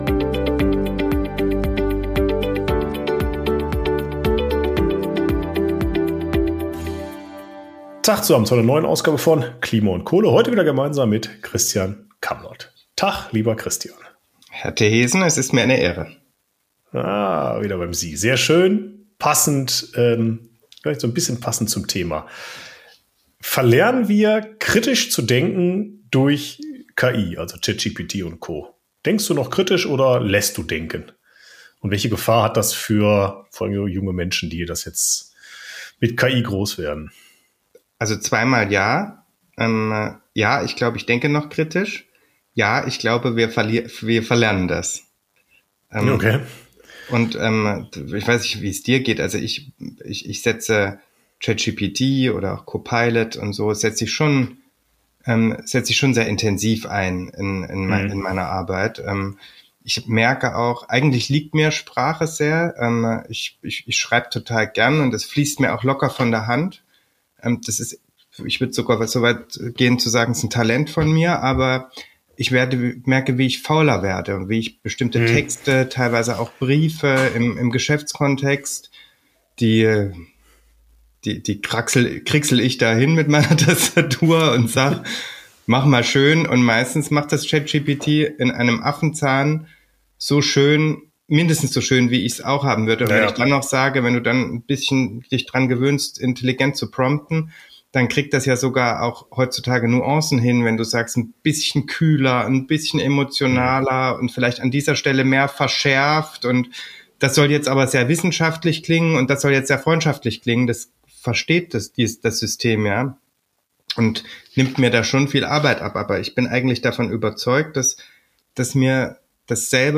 #174 Kein kritisches Denken mehr wegen KI? Gespräch